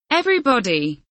everybody kelimesinin anlamı, resimli anlatımı ve sesli okunuşu